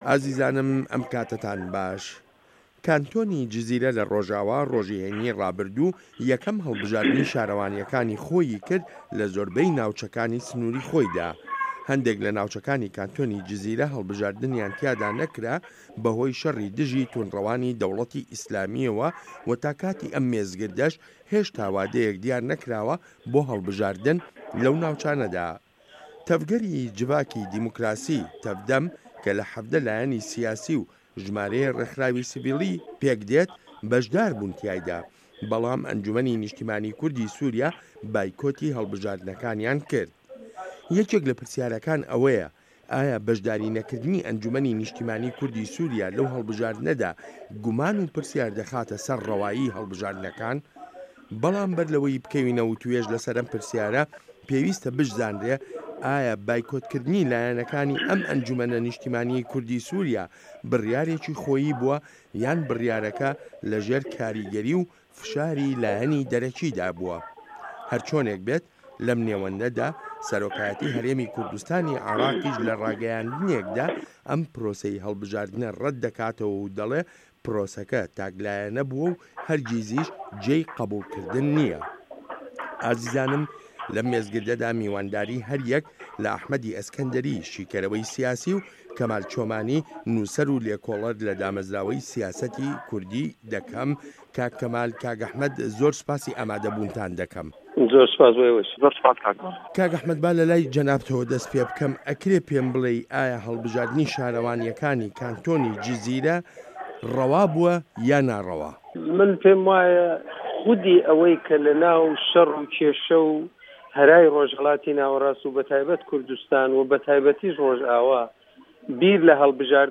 مێزگرد: پره‌نسیپی ڕه‌وایی له‌ هه‌ڵبژاردنه‌کانی کانتۆنی جزیره‌دا